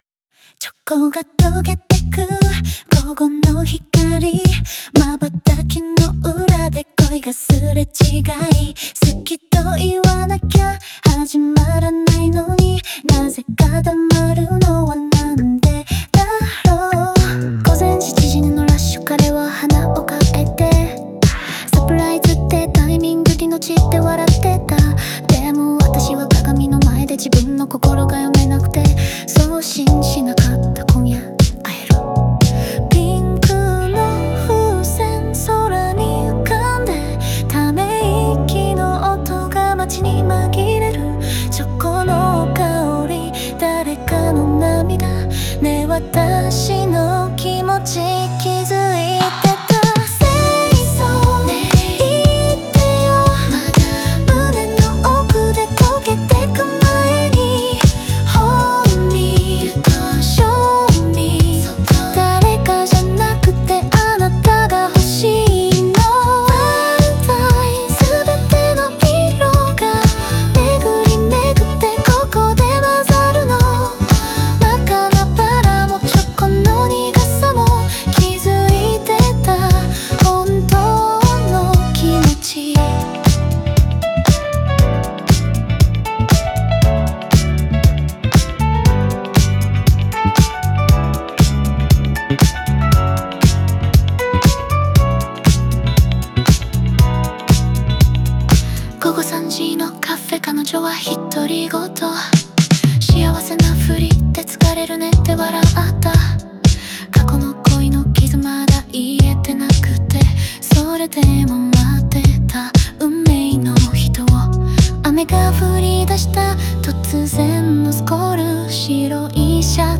グルーヴィーで都会的なサウンドと共に、恋の一瞬一瞬が軽やかに、でも切なく響きます。